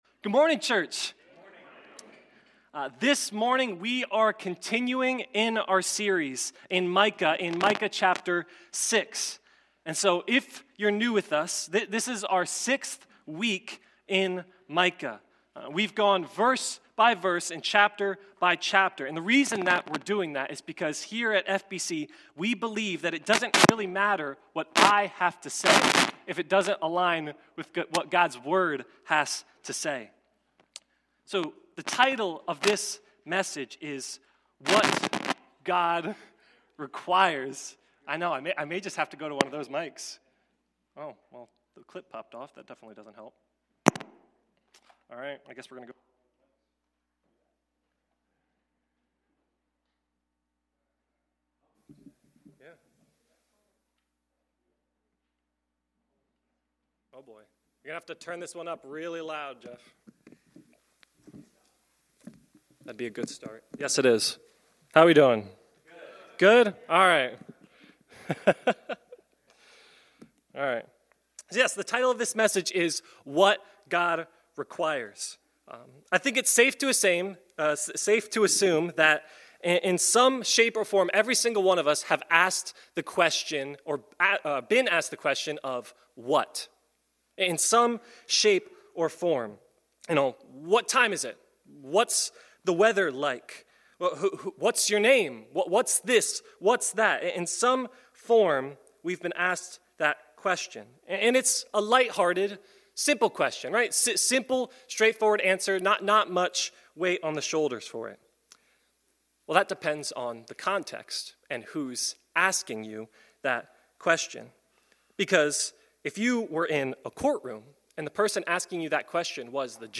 Sunday Morning Micah: Who is like the Lord?